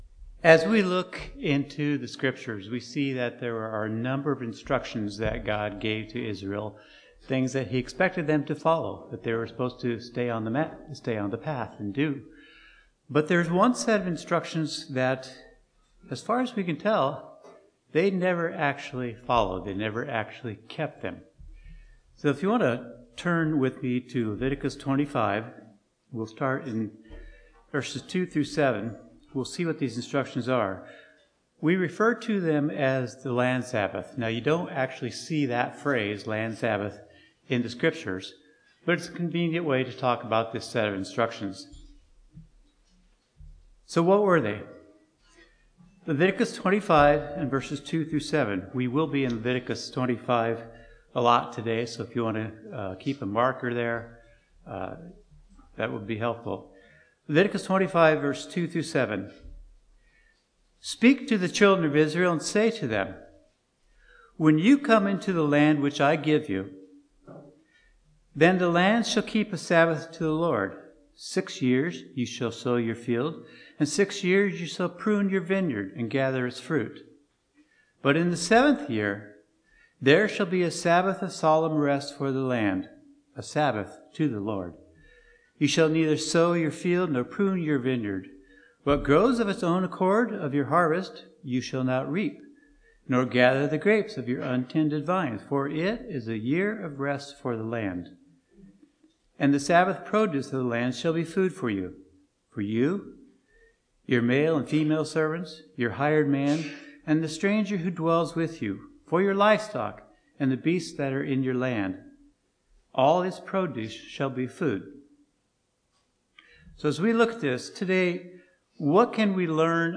Sermons
Given in Northwest Indiana